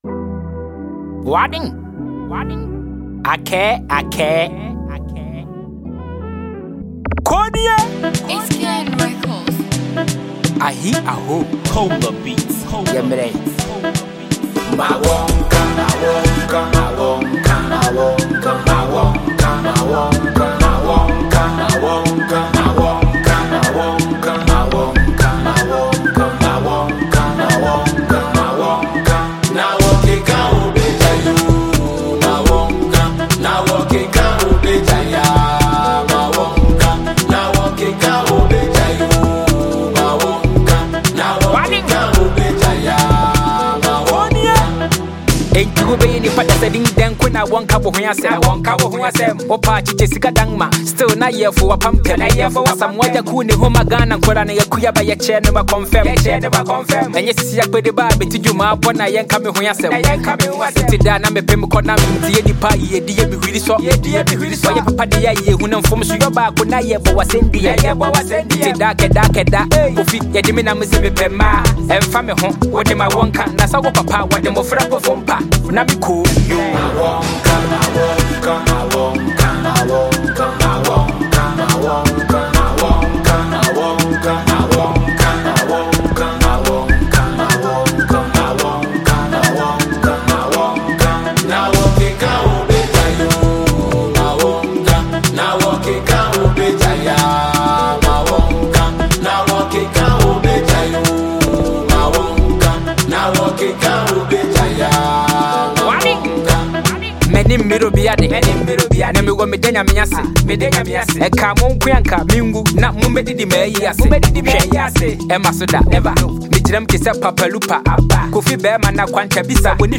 Ghanaian rapper
a powerful and unapologetic single